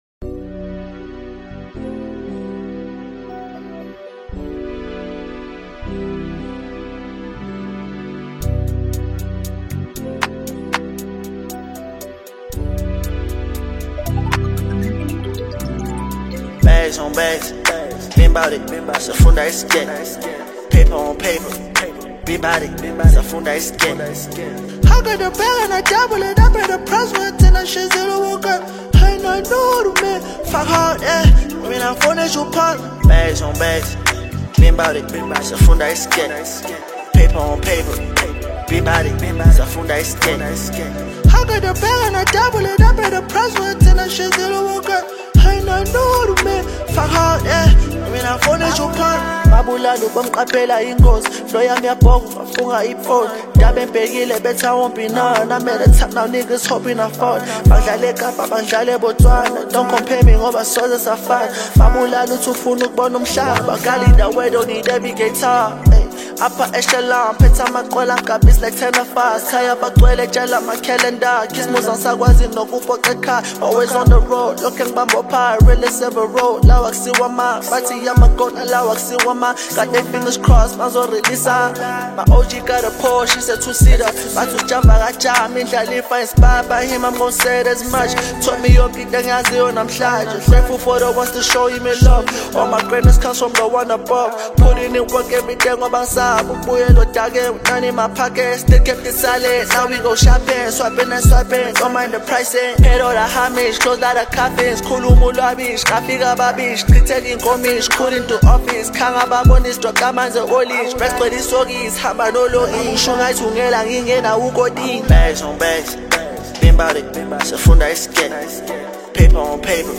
Home » Amapiano » Gqom » Latest Mix